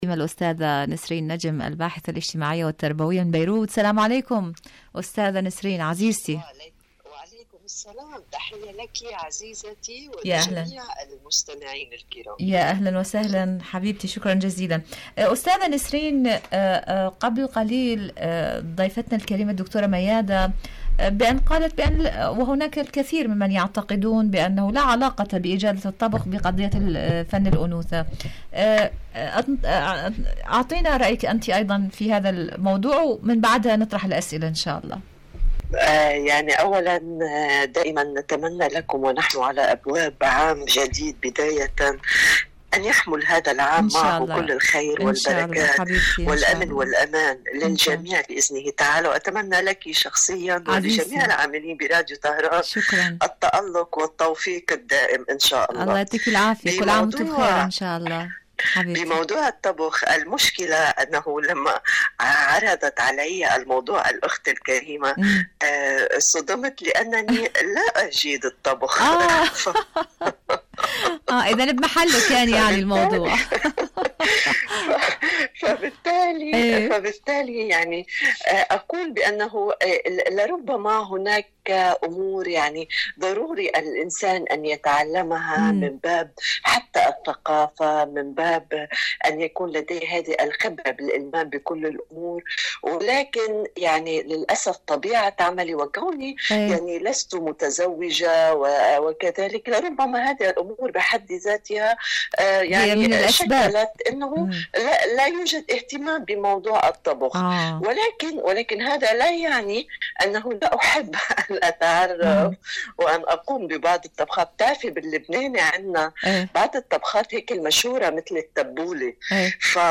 مقابلات برامج إذاعة طهران العربية برنامج عالم المرأة المرأة الطبخ الأنوثة إجادة الطبخ الأسرة عالم المرأة مقابلات إذاعية شاركوا هذا الخبر مع أصدقائكم ذات صلة مواقف طهران من تهديدات ترامب..